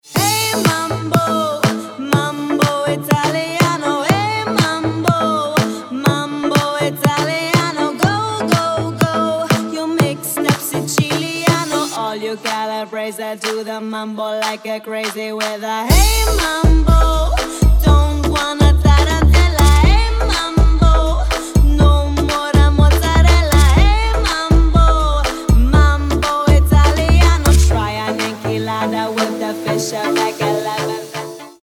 • Качество: 320, Stereo
deep house
dance
club
Vocal House